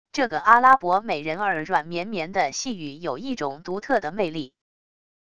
这个阿拉伯美人儿软绵绵的细语有一种独特的魅力wav音频